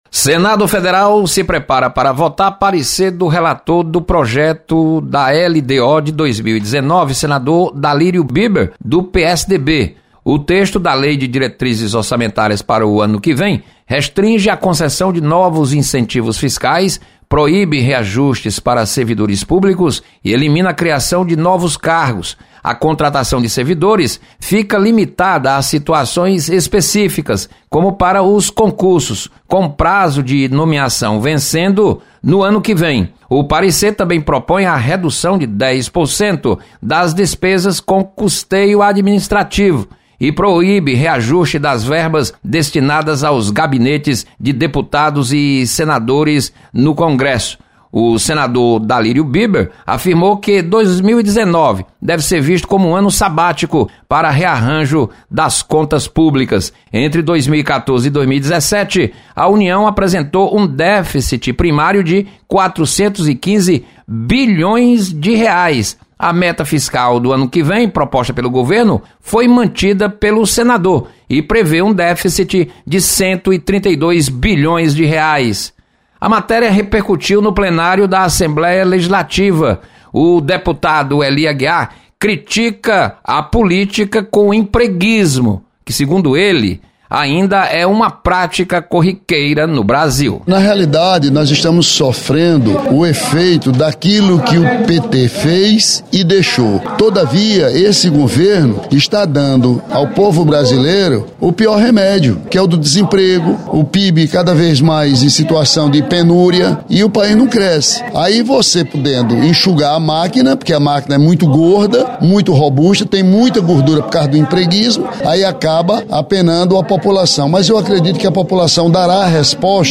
Arrocho no Orçamento da União preocupa deputados na Assembleia Legislativa. Repórter